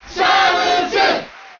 File:Samus Cheer Japanese SSBM.ogg
Samus_Cheer_Japanese_SSBM.ogg